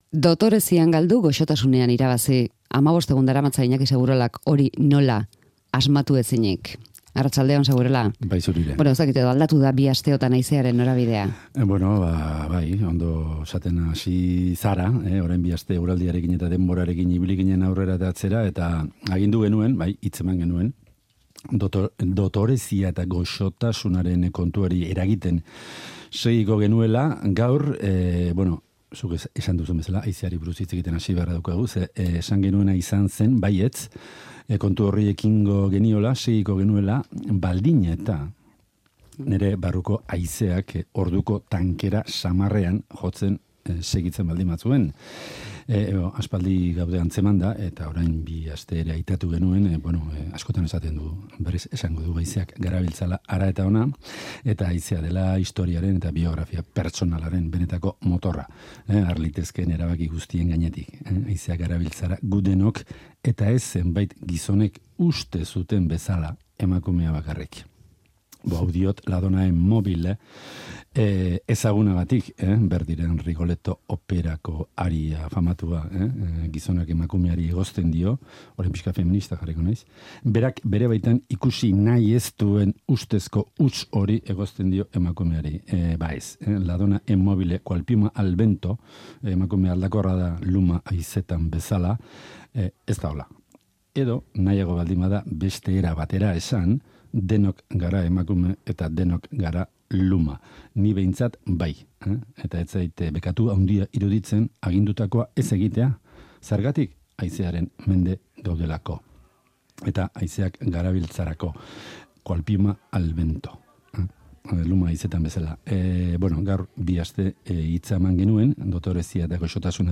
Audioa: Gaur hamabost amaitu zuen toki beretik abiatuta gaurko sermoia.